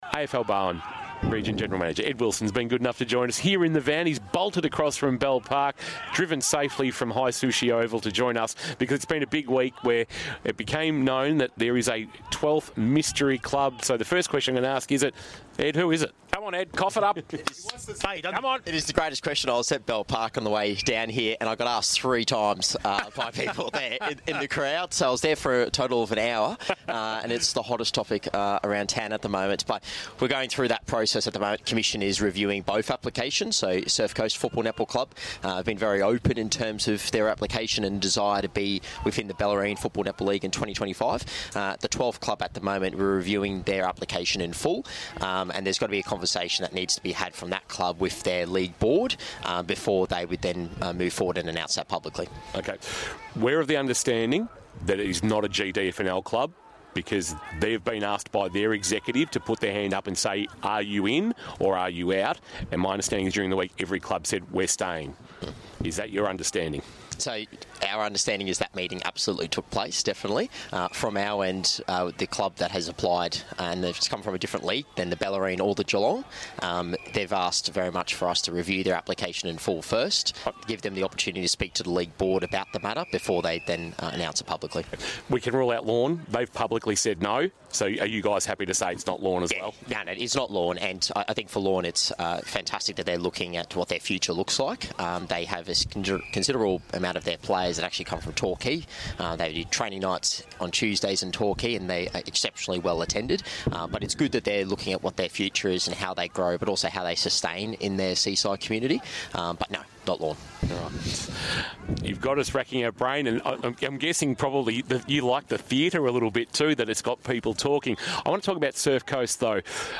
2024 - GFNL - Round 5 - St Albans vs. Geelong West: Pre-match interview